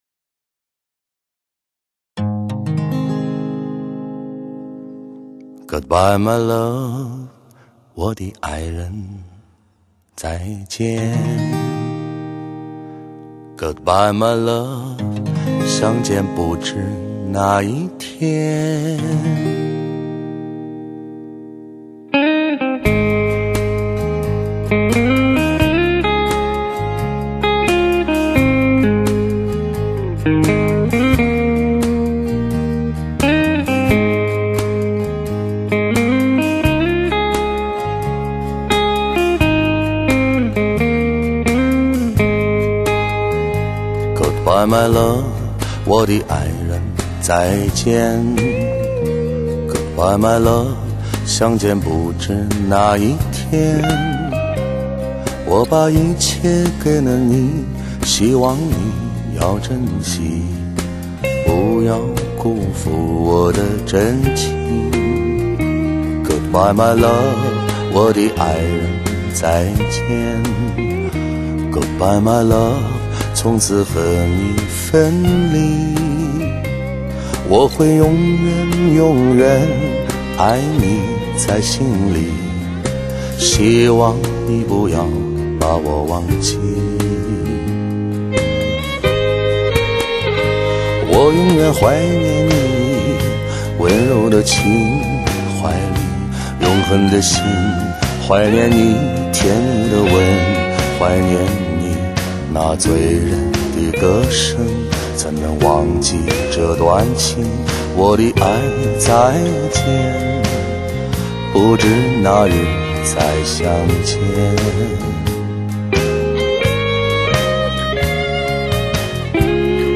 中的人声清唱，也极有说服力，细节、口型和空间感难得一见的好！